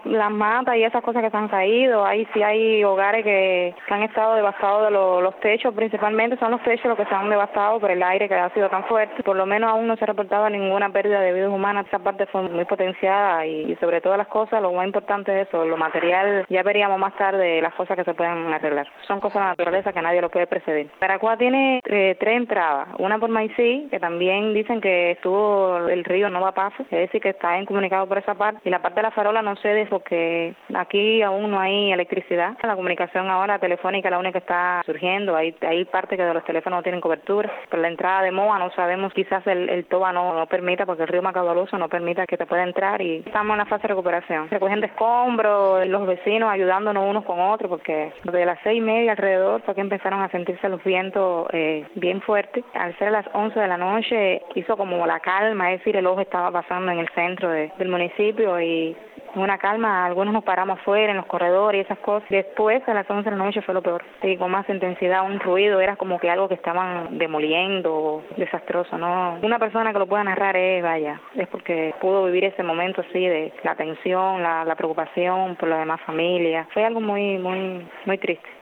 Matthew pasó como un "animal horrible", así lo contaron los oyentes de Radio Martí